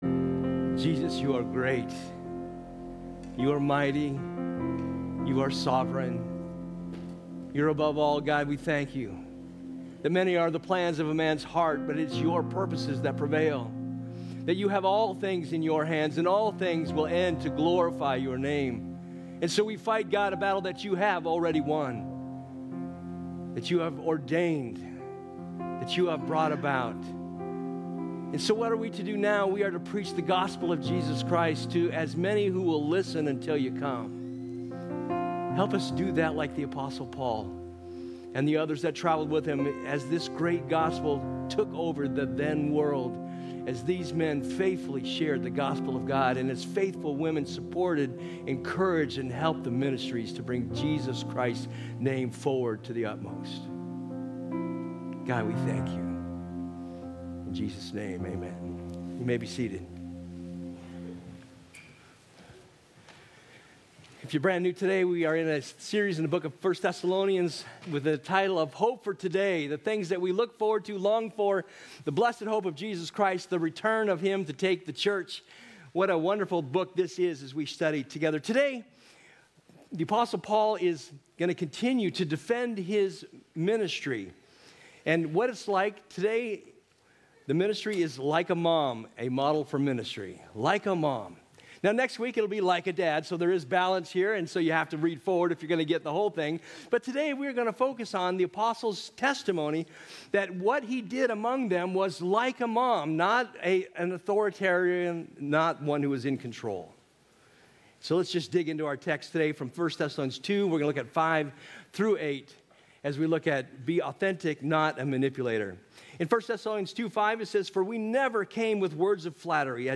Sermon Archive | Avondale Bible Church